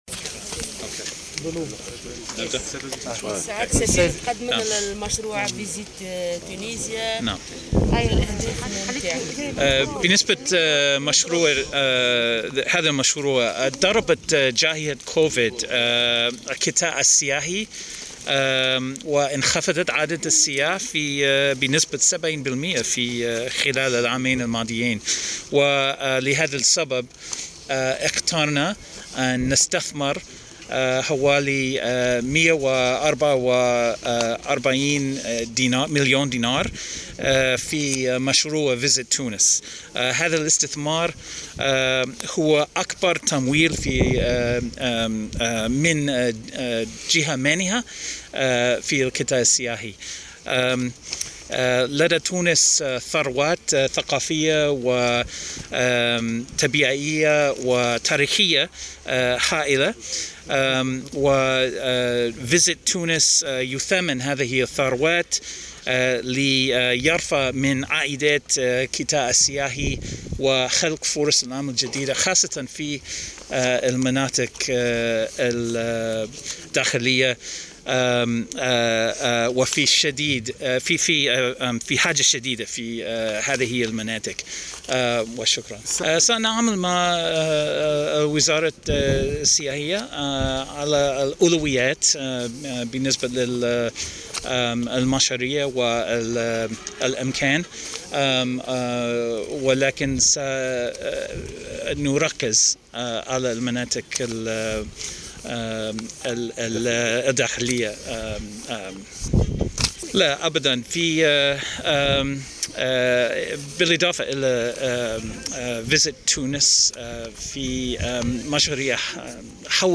وأكد سفير الولايات المتحدة بتونس، دونالد بلوم، في تصريح لمراسل الجوهرة اف ام بالمناسبة، إنه سيتم من خلال هذا المشروع التركيز على المناطق الداخلية التونسية لإدماجها وتثمين منتجات السياحة البديلة في هذه المناطق، خصوصا في المجالات البيئية والثقافية.